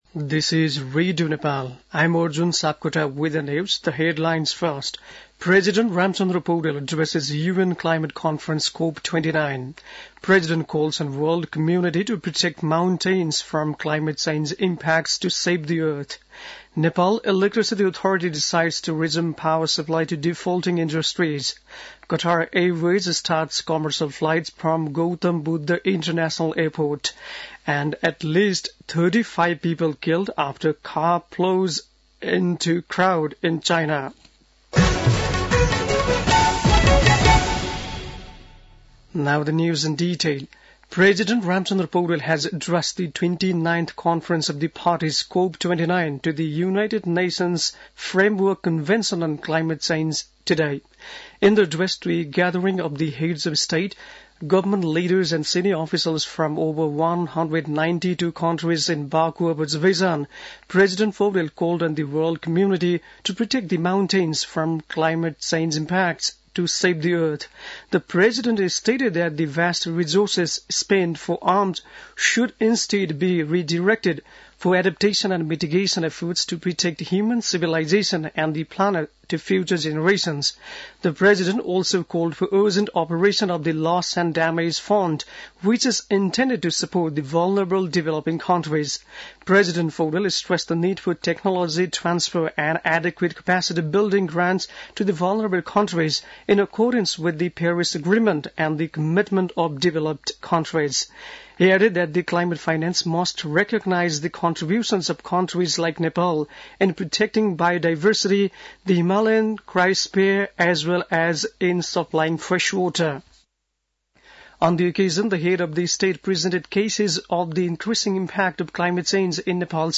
बेलुकी ८ बजेको अङ्ग्रेजी समाचार : २८ कार्तिक , २०८१